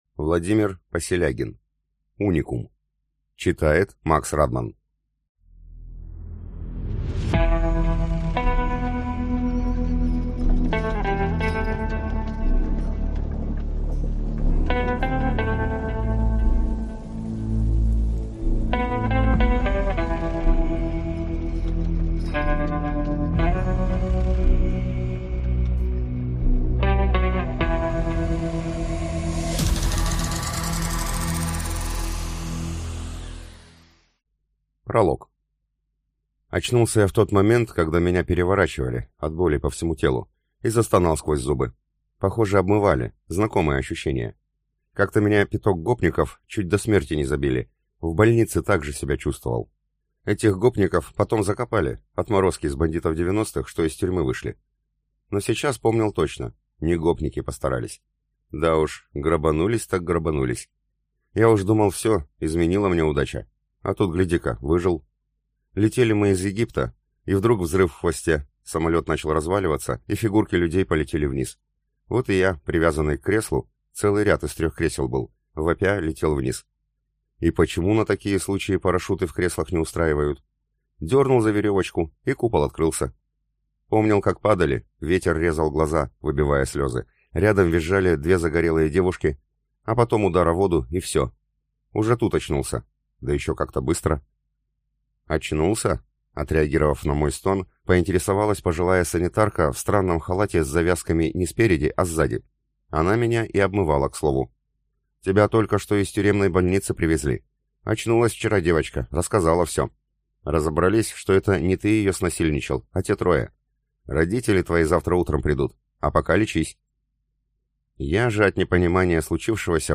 Аудиокнига Уникум | Библиотека аудиокниг